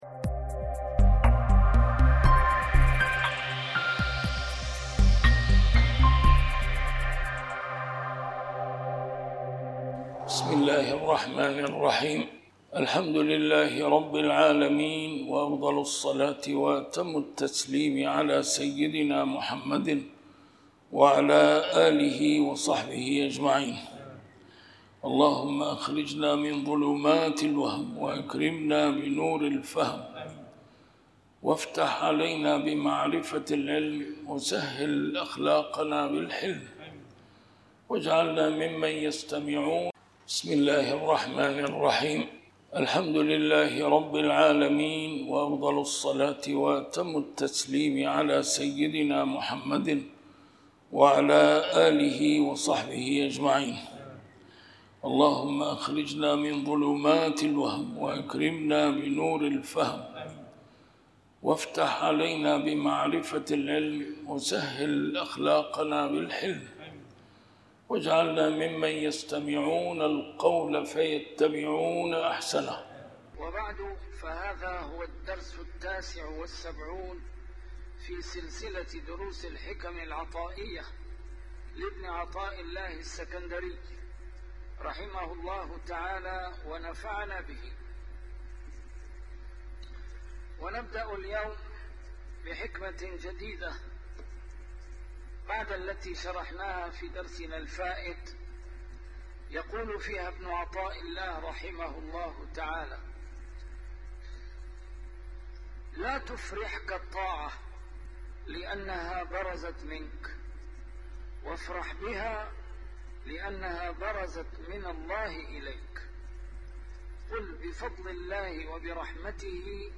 A MARTYR SCHOLAR: IMAM MUHAMMAD SAEED RAMADAN AL-BOUTI - الدروس العلمية - شرح الحكم العطائية - الدرس رقم 79 شرح الحكمة 58